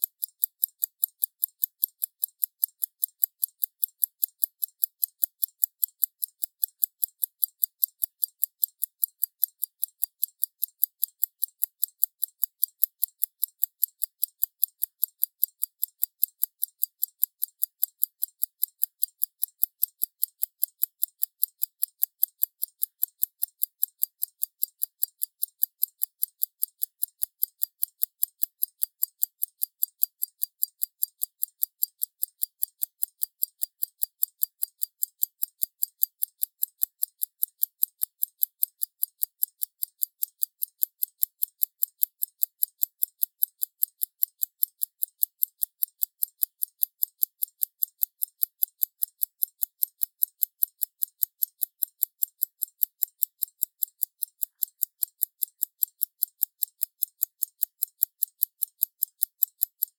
To remove the low frequency noise I applied a high-pass 1 kHz cutoff frequency, 5th order Bessel filter.
To remove the white noise I used the noise reduction filter of Audacity, and this considerably improved the quality of the audio.
Filtered normal playback ticking sound
normal-ticking.mp3